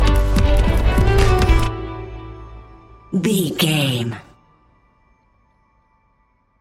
Ionian/Major
D♭
electronic
techno
trance
synthesizer
synthwave
instrumentals